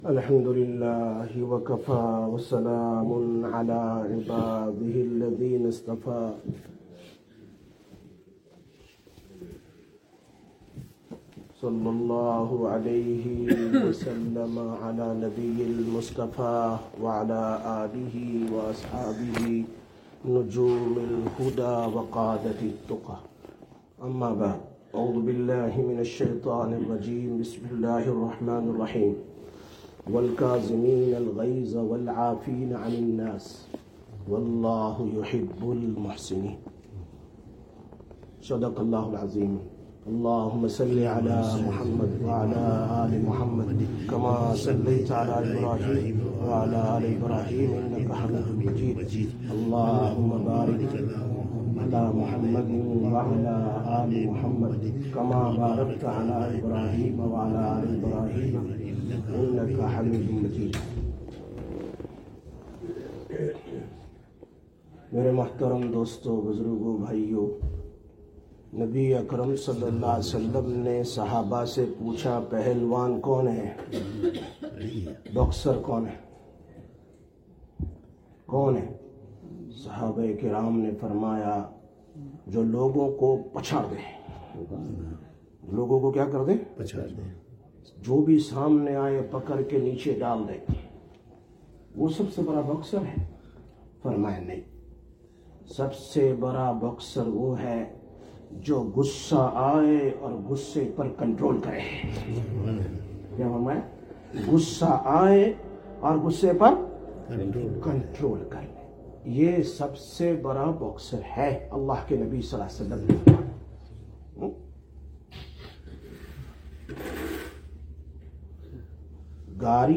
20/06/2025 Jumma Bayan, Masjid Quba